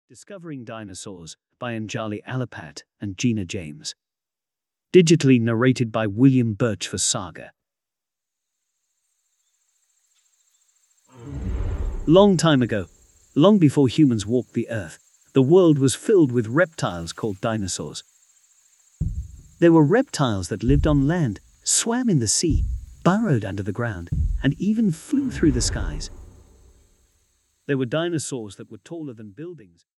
Ukázka z knihy
discovering-dinosaurs-en-audiokniha